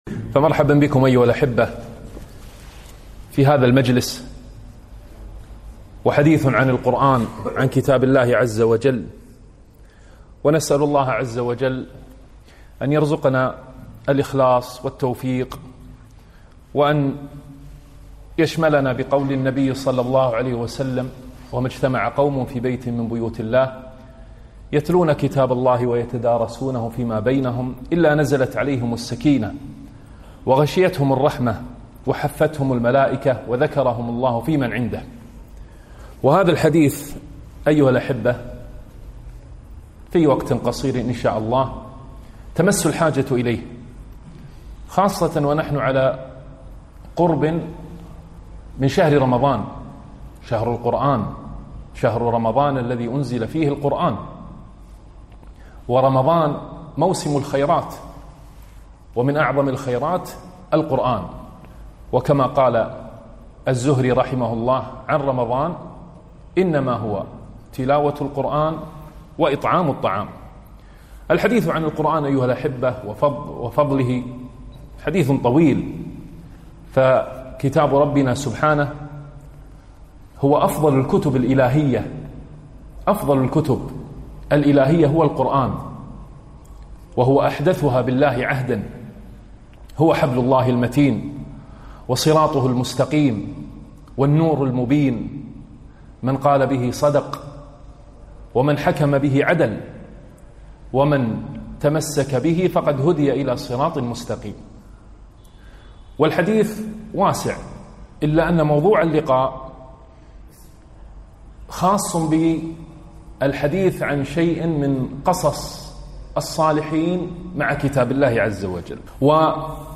محاضرة - قصص الصالحين مع القرآن